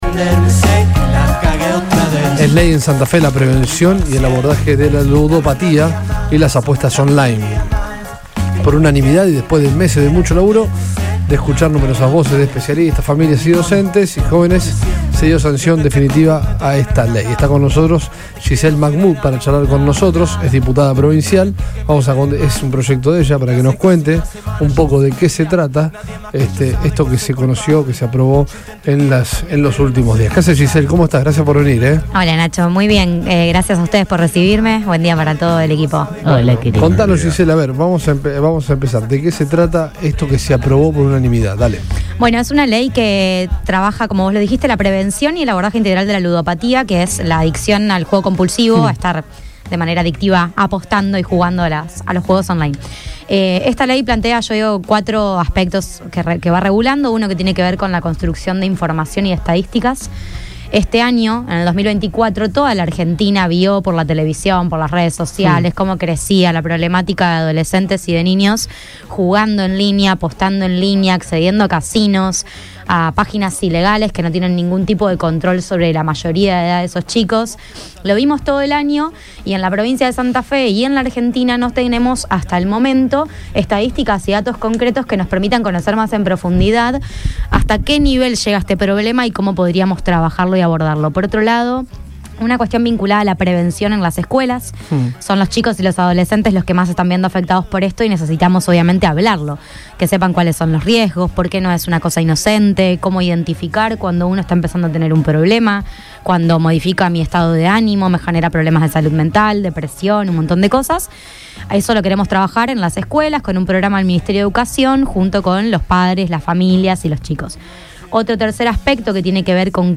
Gisel Mahmud, diputada provincial, visitó al equipo de Todo Pasa turno mañana para brindar detalles sobre la Ley de prevención de Ludopatía, un proyecto que ella impulso y se aprobó en los últimos días.